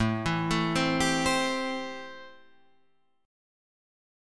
Am7 chord